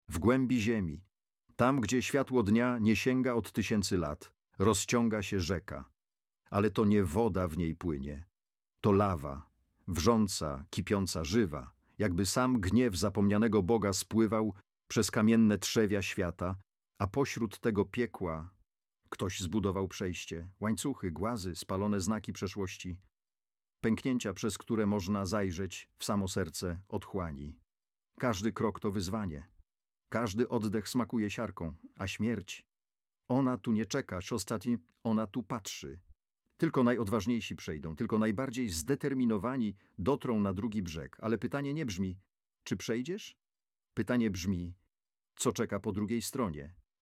📜 Narracja
polmrok-lektor-brod-przez-rzeke-lawy.mp3